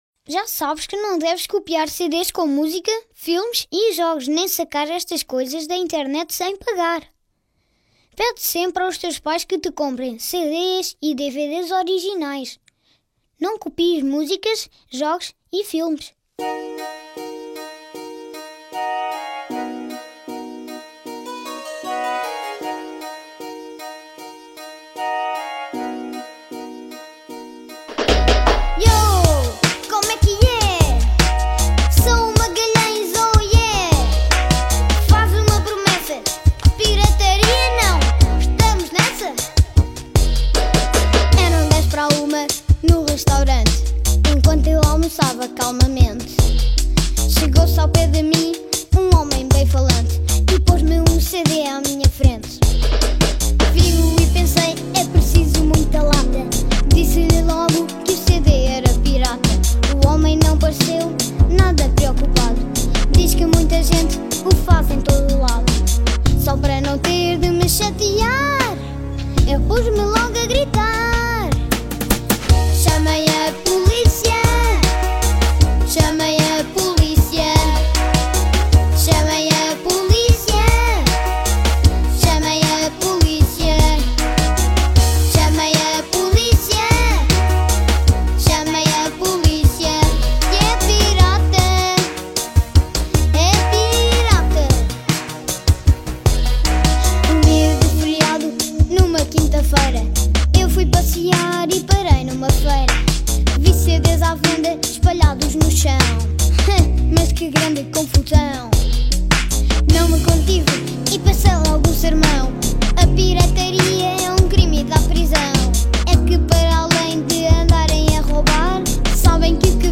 E que tal este hino anti-pirataria de 2010s